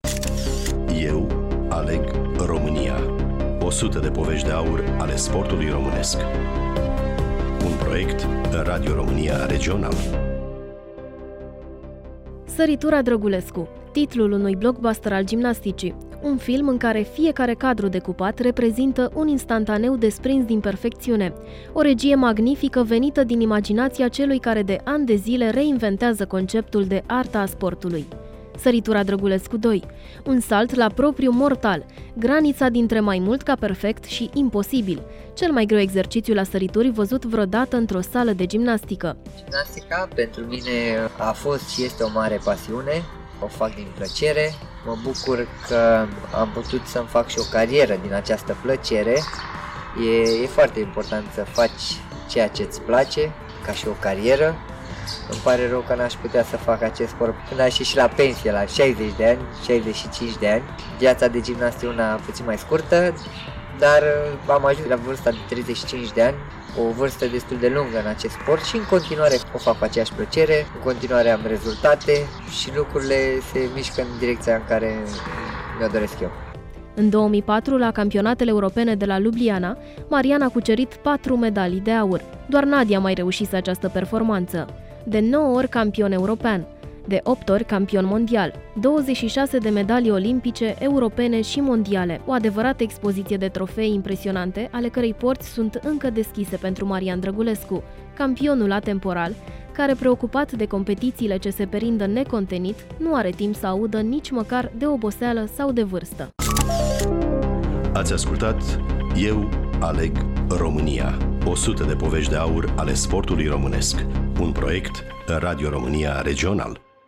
Studioul: Bucuresti FM